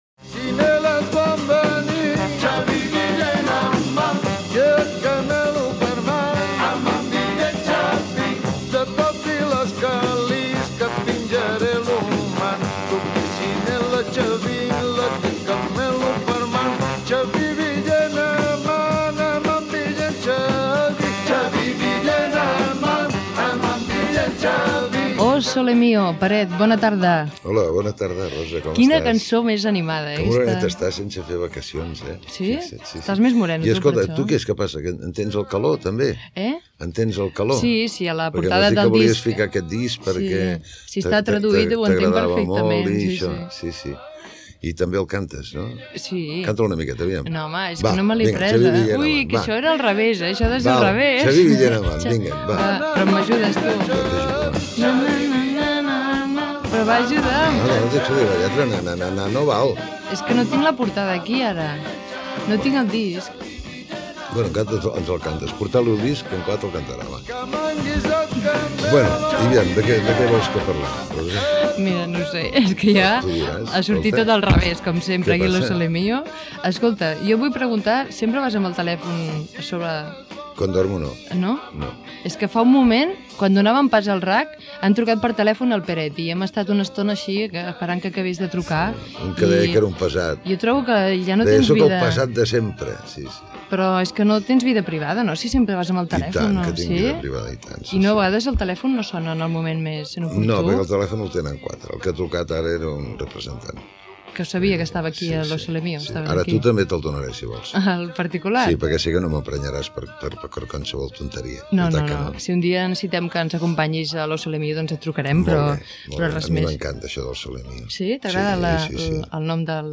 Entrevista al cantant Peret (Pere Pubill Calaf), el tema rumbero "Chaví", telèfons de participació, trucada telefònica d'un oïdor
Entreteniment